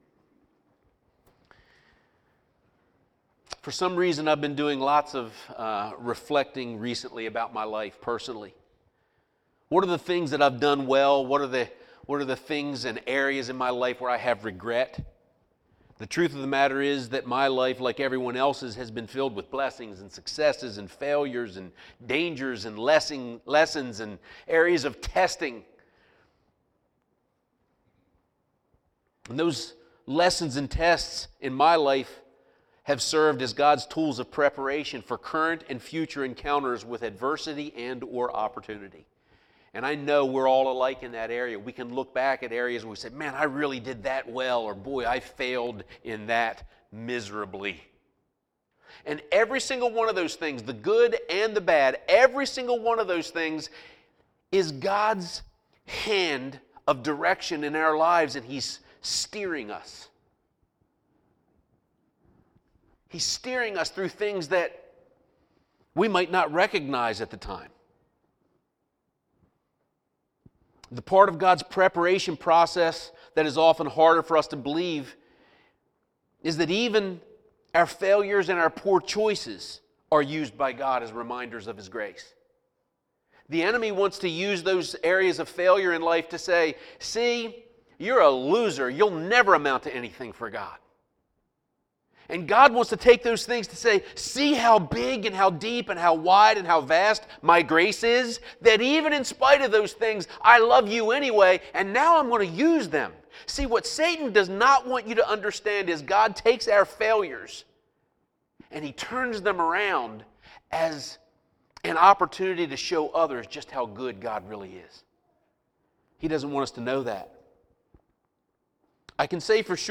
2-24-19-sermon.mp3